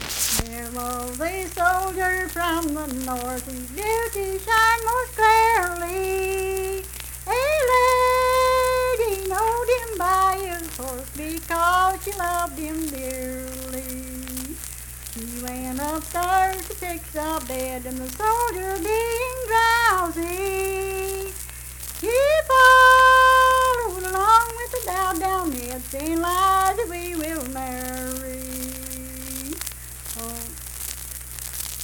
Unaccompanied vocal music performance
Verse-refrain, 2(4).
Voice (sung)
Mingo County (W. Va.), Kirk (W. Va.)